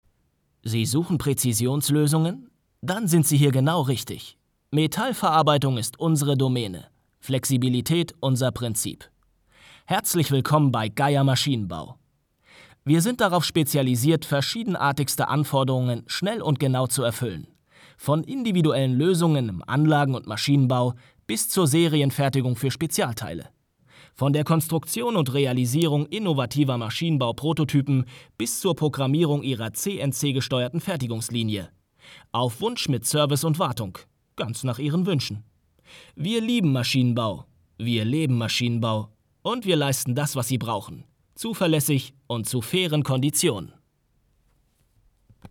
klare, sanfte und ausdrucksstarke Stimme mit Wiedererkennungswert / auch Trickstimme
Sprechprobe: Industrie (Muttersprache):